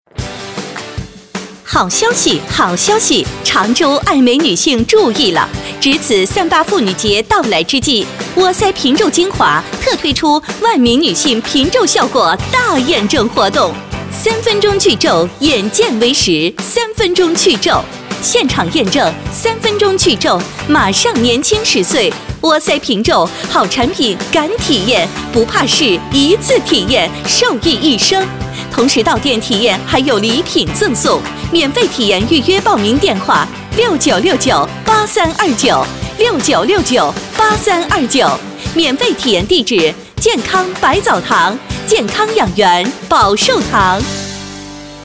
A女4号
【促销】美容去皱三八妇女节女4-激情
【促销】美容去皱三八妇女节女4-激情.mp3